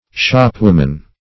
shopwoman - definition of shopwoman - synonyms, pronunciation, spelling from Free Dictionary Search Result for " shopwoman" : The Collaborative International Dictionary of English v.0.48: Shopwoman \Shop"wom`an\, n.; pl.